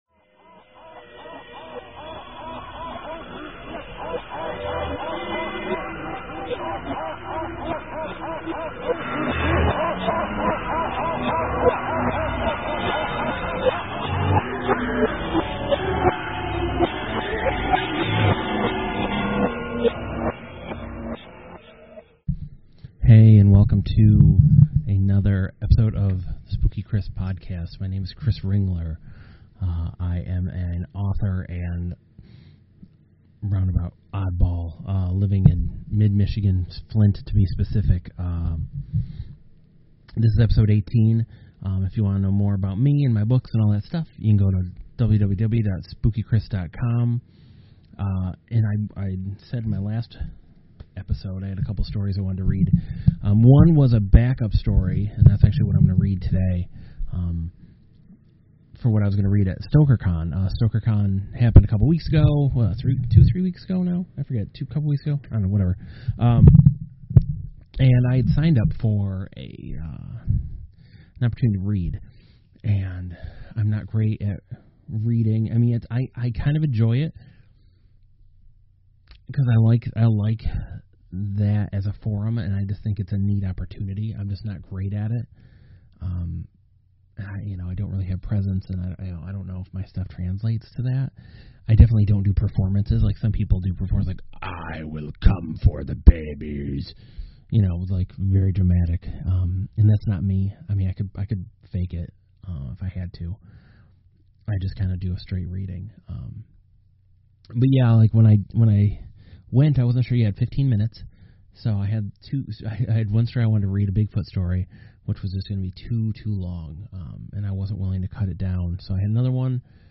In which I read my short story SOMETHING IN THE WATER.